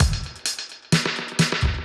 Index of /musicradar/dub-designer-samples/130bpm/Beats
DD_BeatA_130-03.wav